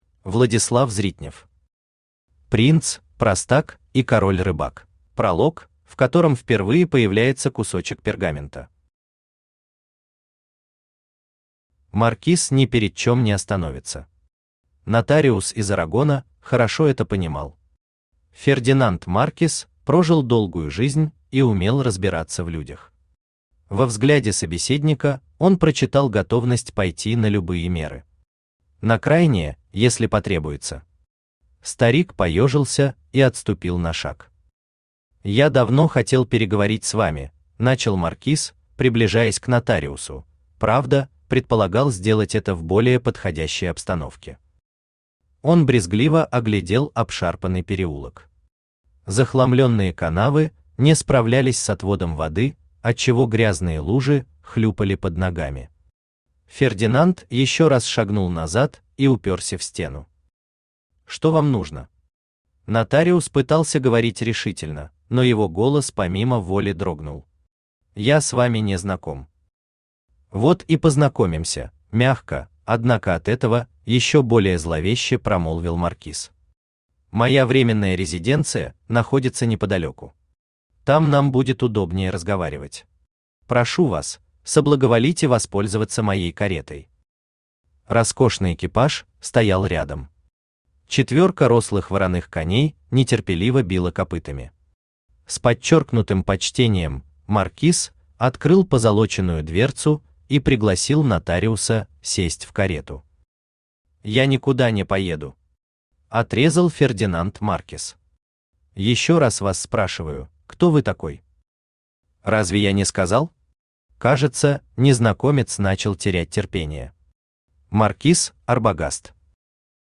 Aудиокнига Принц, простак и король-рыбак Автор Владислав Зритнев Читает аудиокнигу Авточтец ЛитРес.